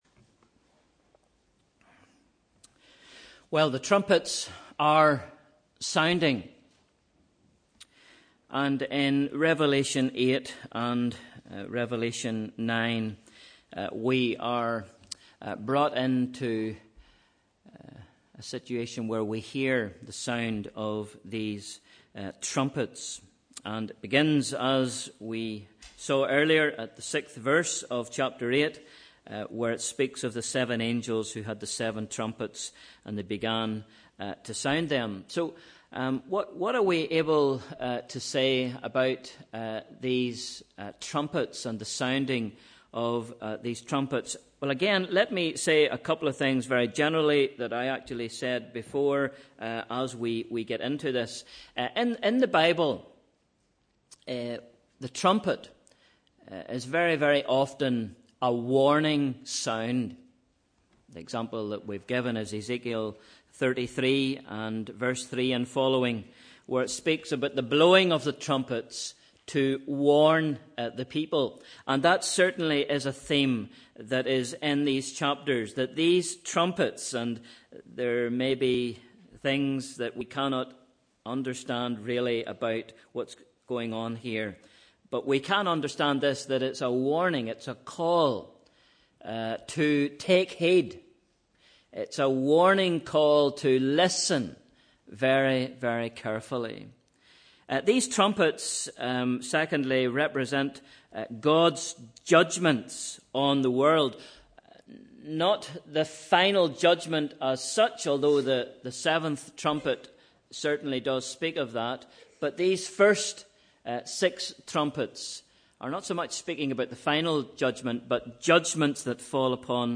Sunday 27th November 2016 – Evening Service